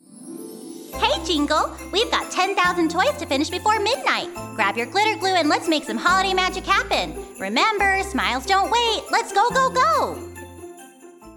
expressive female voice talent